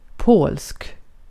Ääntäminen
IPA: /poːlsk/ IPA: /pɔlsk/